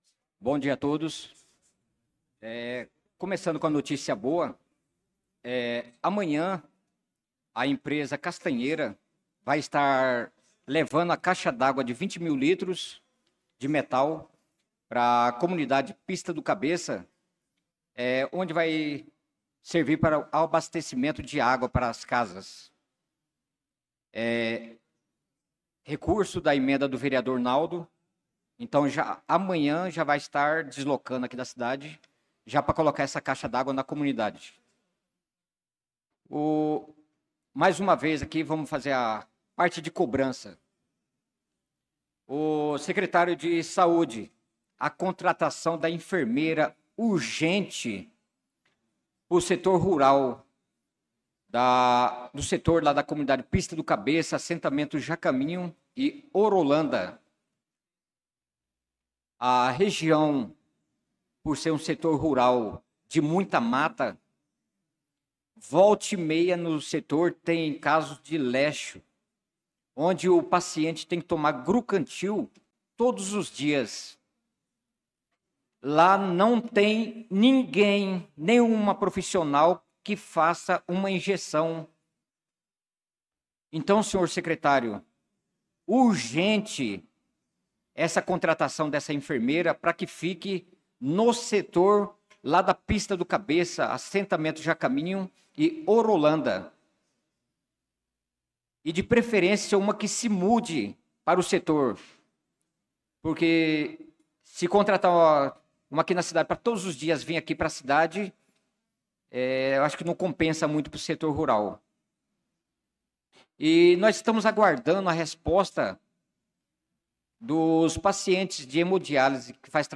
Pronunciamento do vereador Naldo da Pista na Sessão Ordinária do dia 04/08/2025.